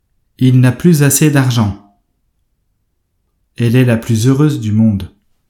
On prononce [plyz]
• Avec les liaisons : il n’a plus‿assez d’argent.
Plus-avec-liaison.mp3